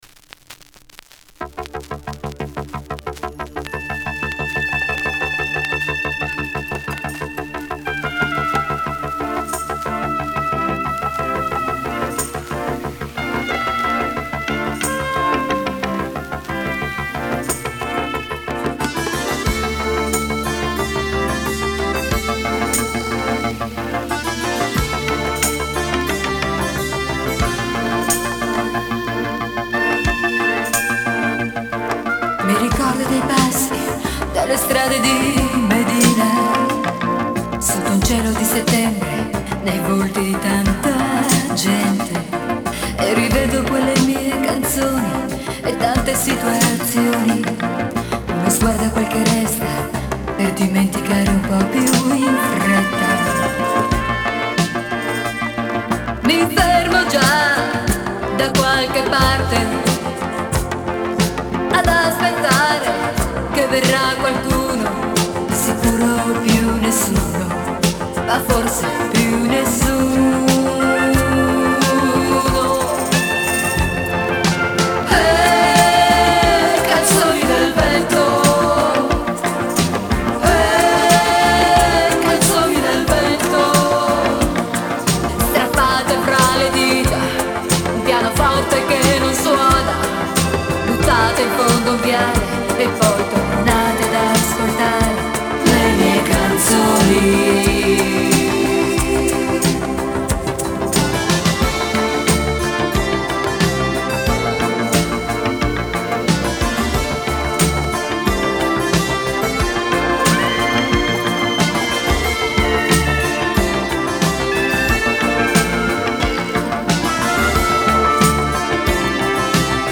Genre:Electronic, Pop
Style:Italo-Disco, Chanson